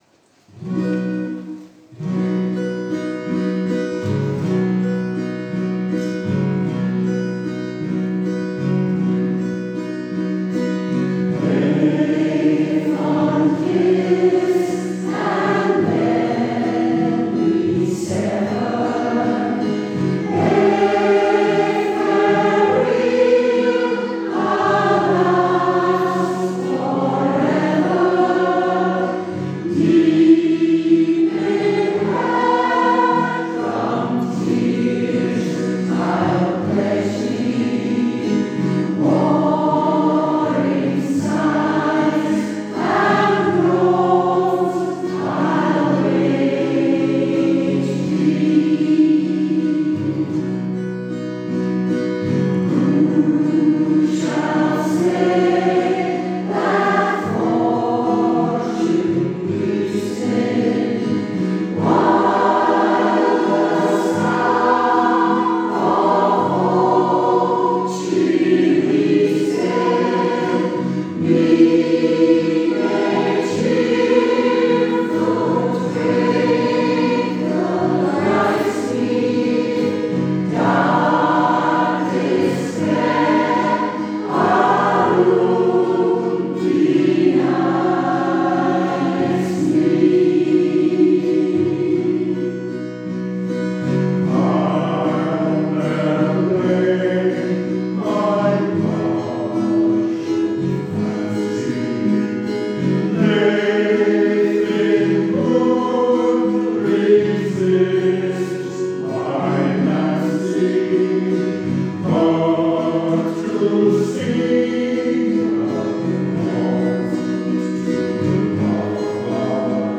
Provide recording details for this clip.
And here are some audio recordings from a recent concert: